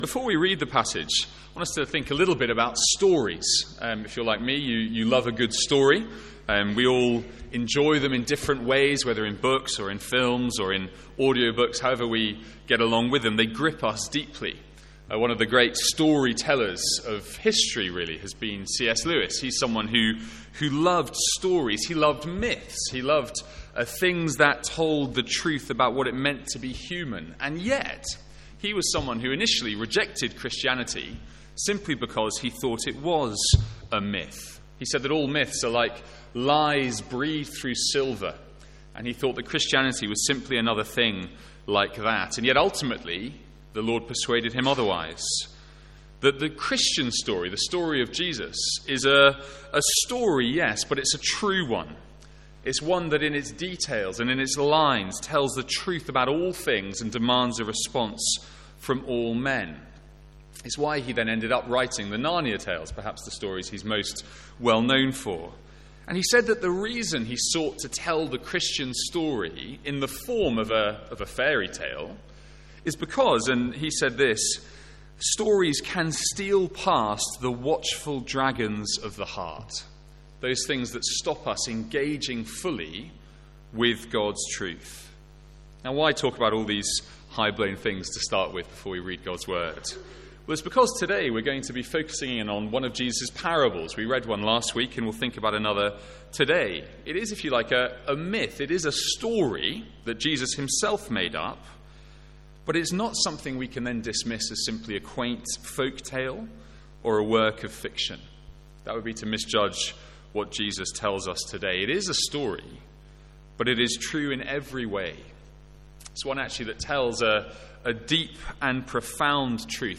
Sermons | St Andrews Free Church